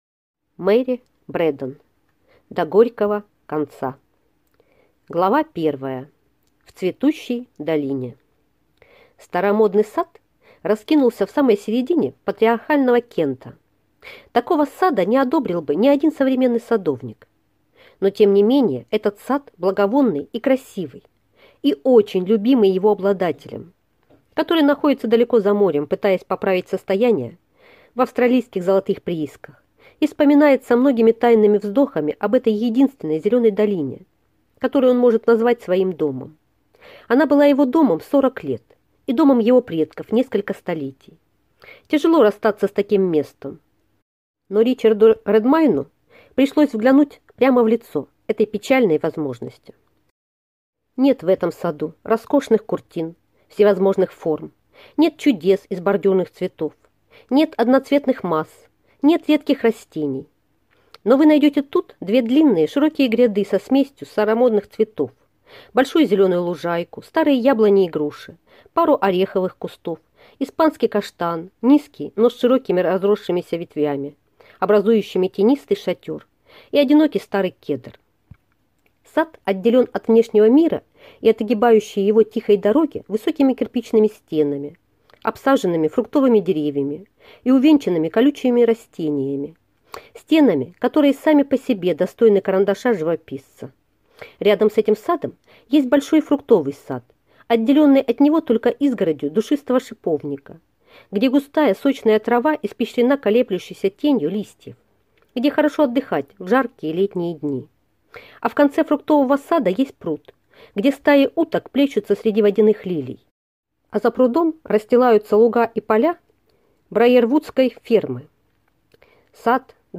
Аудиокнига До горького конца | Библиотека аудиокниг